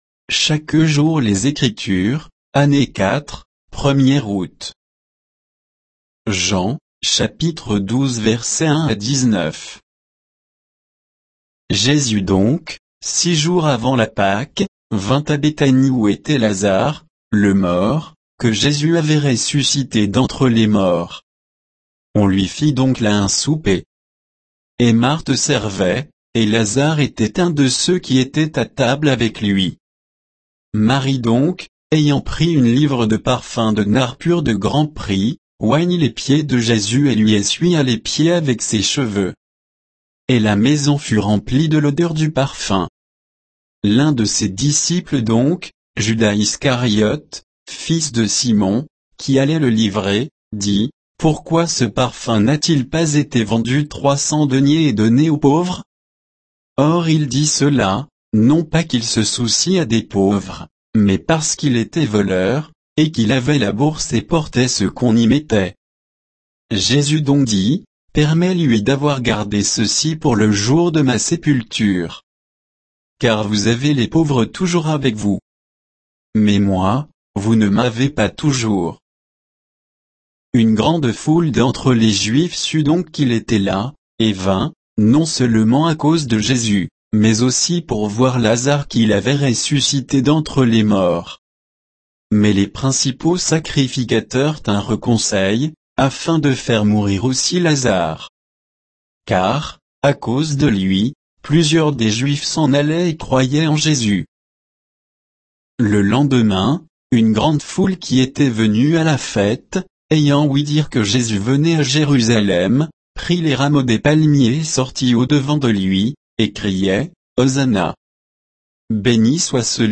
Méditation quoditienne de Chaque jour les Écritures sur Jean 12, 1 à 19